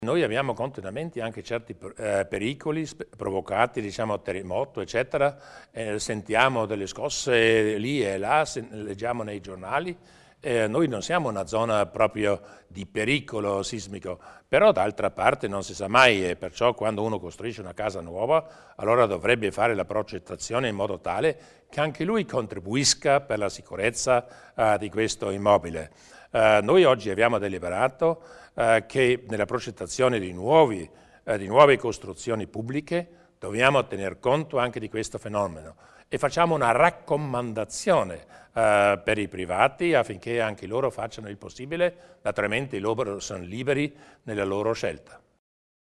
Il Presidente Durnwalder illustra i progetti per la sicurezza edilizia